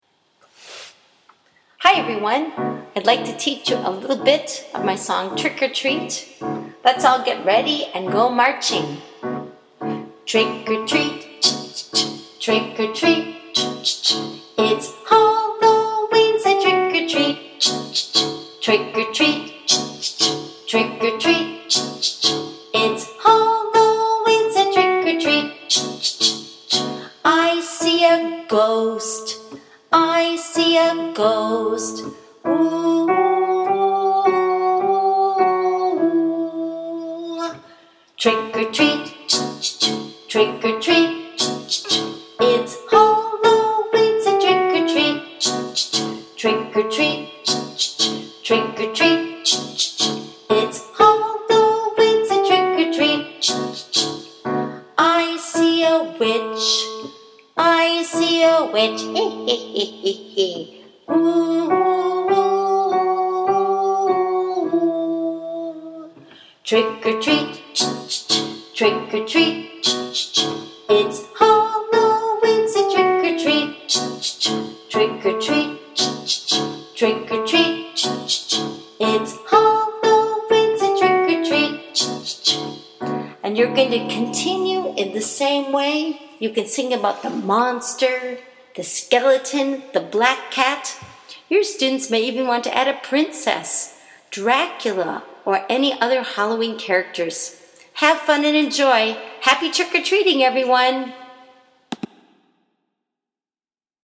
Here’s a simple version of it. This is an original melody that’s easy to sing.